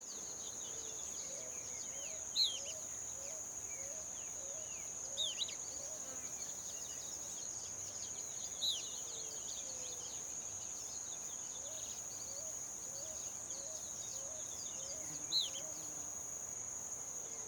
Enferrujado (Lathrotriccus euleri)
Nome em Inglês: Euler´s Flycatcher
Detalhada localização: La Bianca - Camino al Río Uruguay
Condição: Selvagem
Certeza: Fotografado, Gravado Vocal